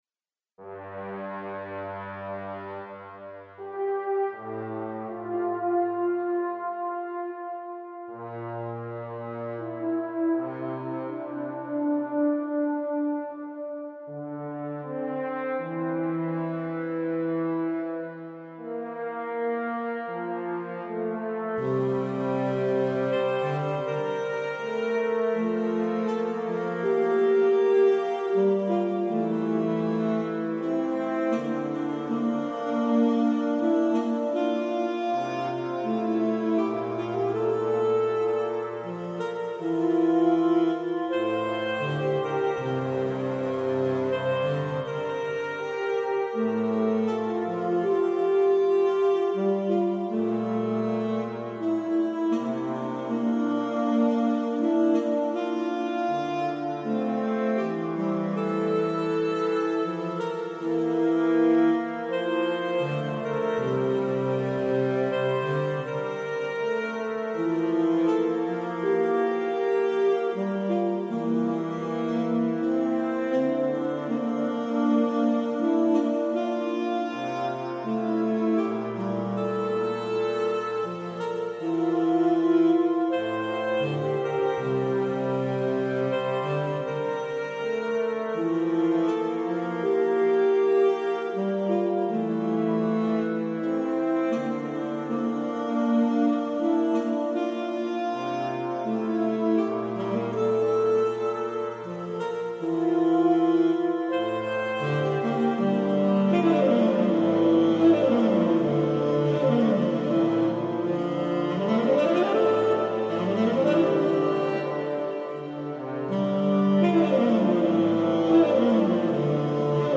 Scored for Tenor Saxophone and Trombone